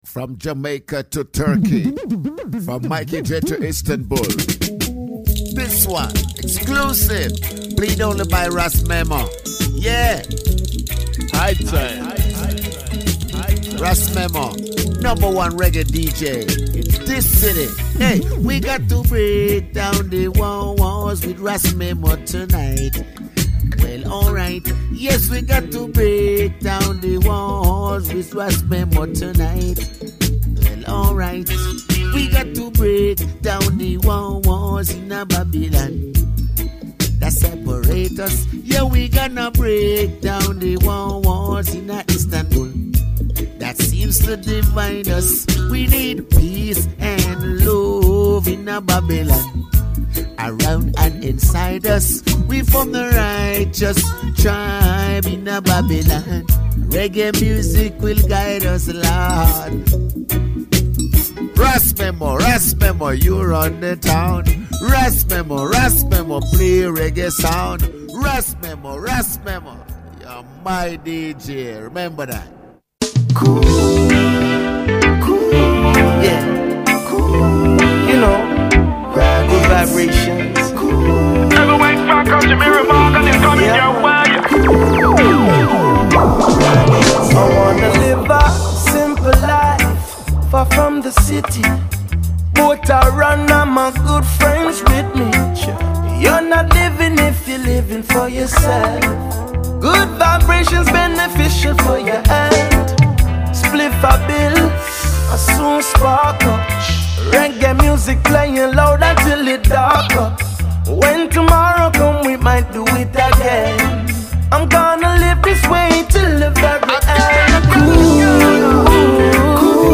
High Times in Radio Show